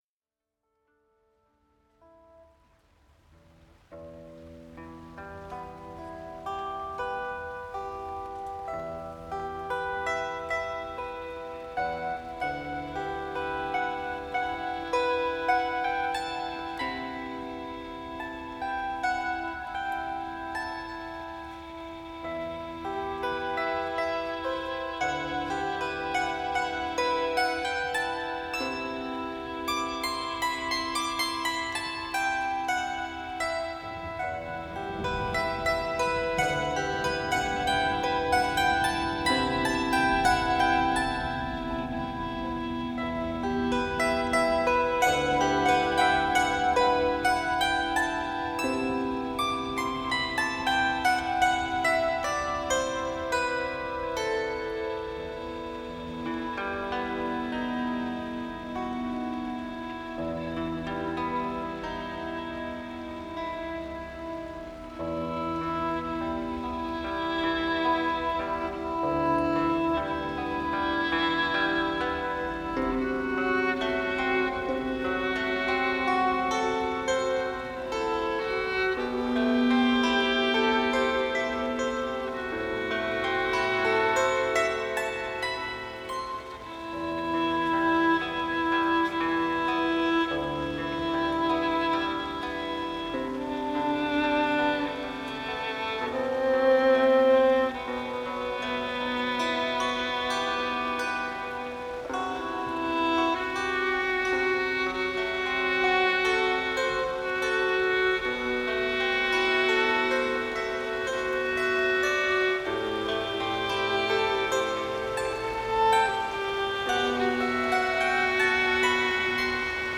Dear listeners, it is with great joy and pride that we bring you these soulful offerings by Sri Chinmoy’s students, performed on the occasion of his 3rd Mahasamadhi Anniversary, October 11, 2010.
Students travelled from all over the world to join in moments of soulful meditation and music performances, in which they performed their arrangements of Sri Chinmoy’s devotional songs.